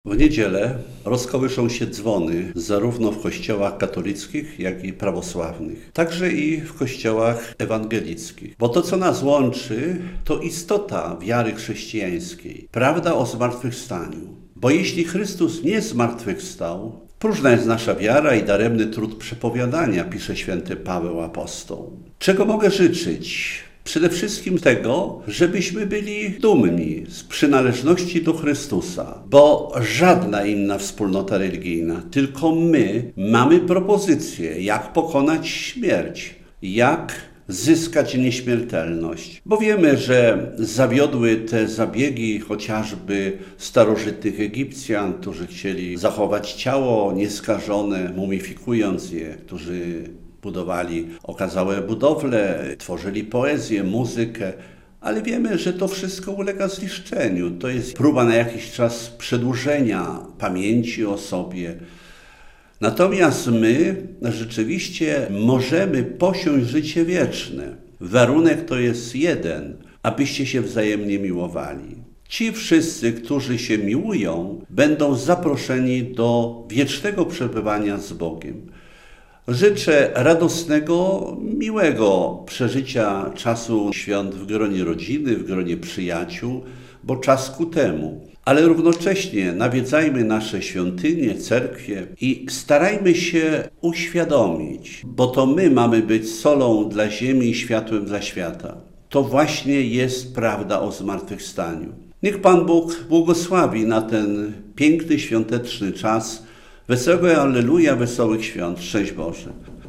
Słuchaj: Życzenia składa ordynariusz diecezji białostocko-gdańskiej abp Jakub | Pobierz plik. Słuchaj: Życzenia składa metropolita białostocki abp Józef Guzdek | Pobierz plik.